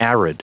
Prononciation du mot arid en anglais (fichier audio)
Prononciation du mot : arid